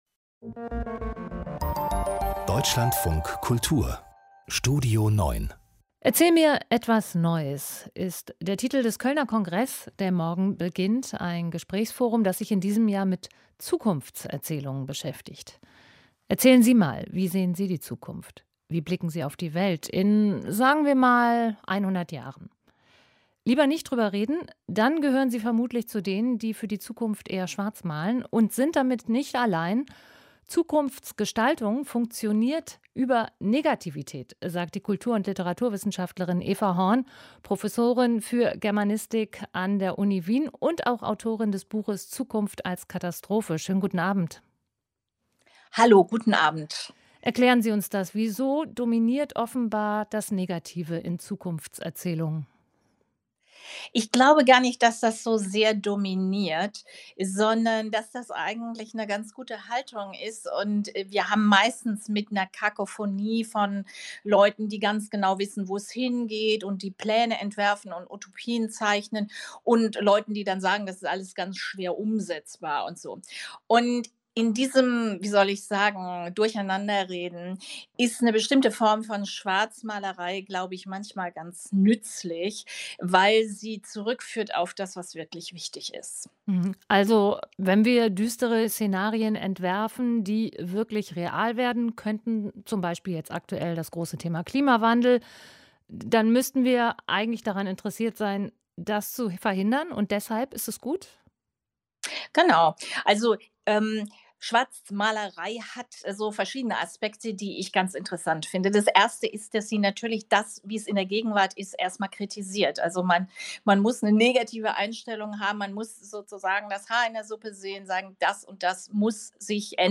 Aus dem Podcast Studio 9 Podcast abonnieren Podcast hören Podcast Studio 9 Der Überblick mit Hintergrund: Interviews, Reportagen, Kritiken – mit originellen...